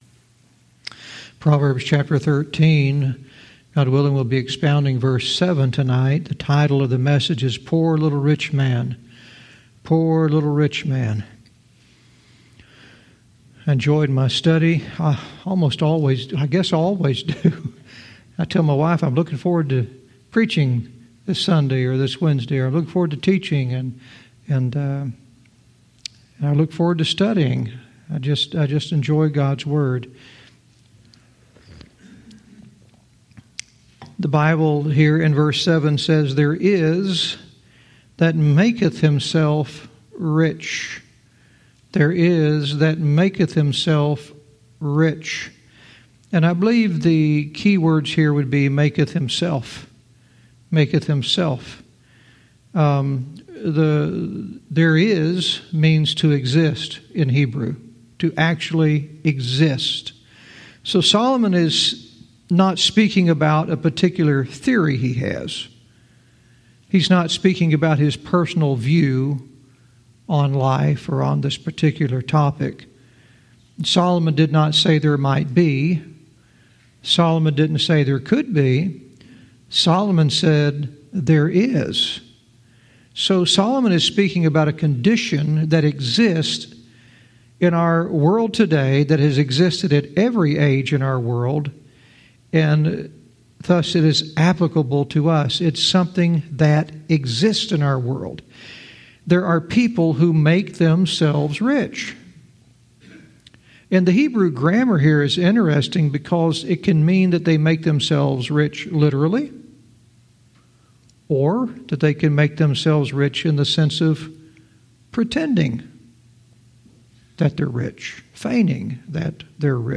Verse by verse teaching - Proverbs 13:7 "Poor Little Rich Man"